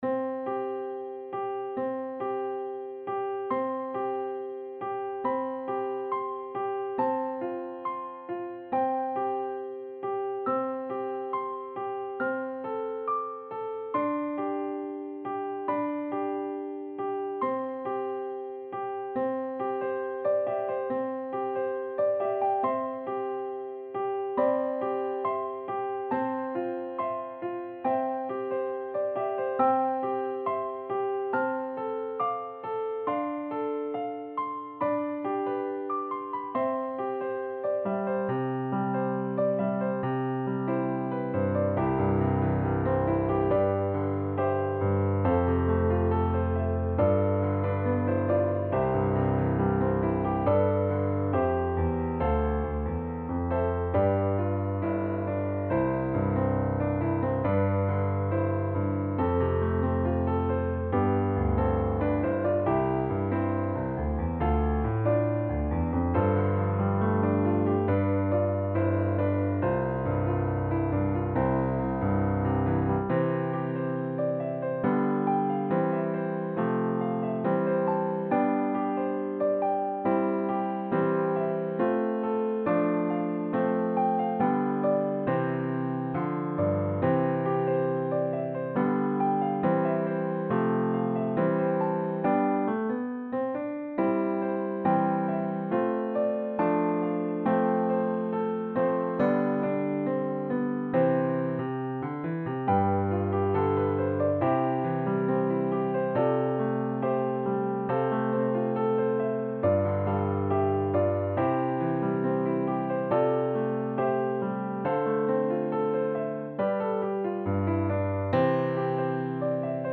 • Level: Intermediate Piano Solo